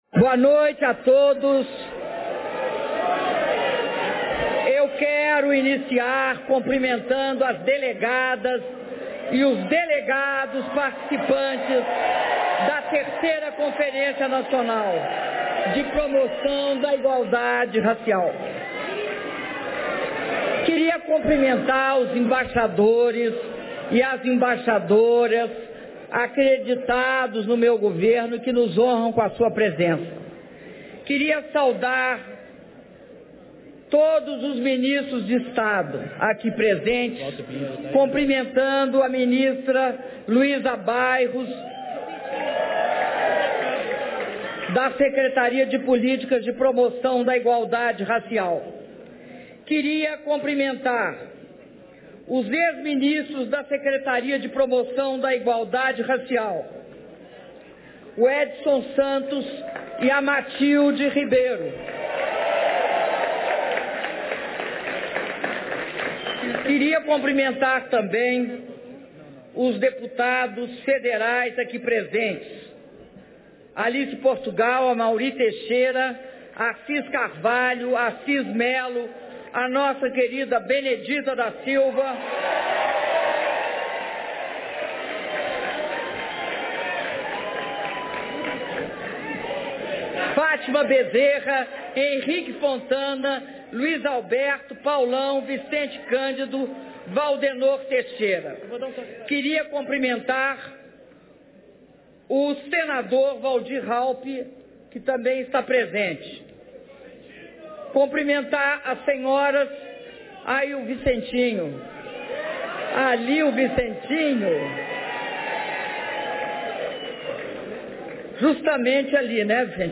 Áudio do discurso da Presidenta da República, Dilma Rousseff, durante a III Conferência Nacional de Promoção da Igualdade Racial - III CONAPIR - Brasília/DF